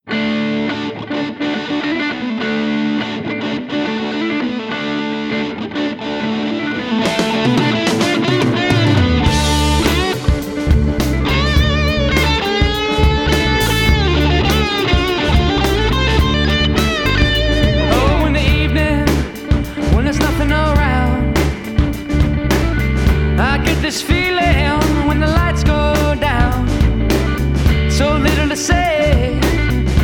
Lead Guitar and Vocals
Bass and Keys